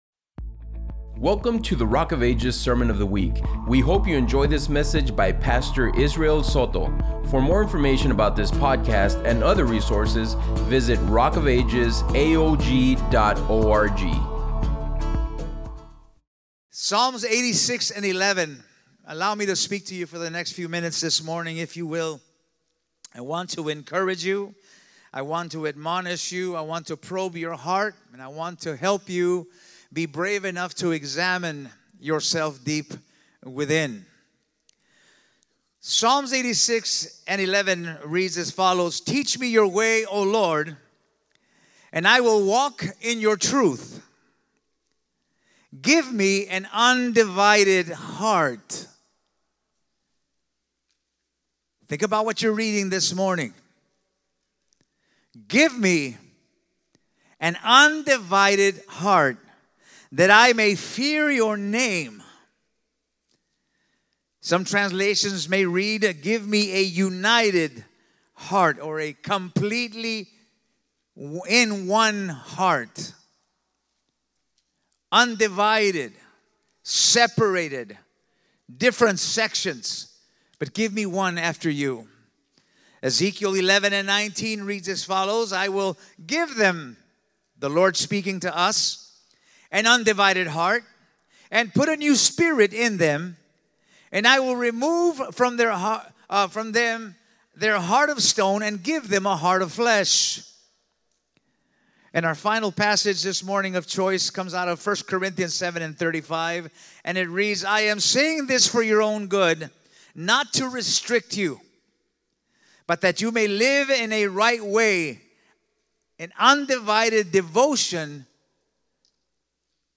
1-19-25-ENG-Sermon-Podcast.mp3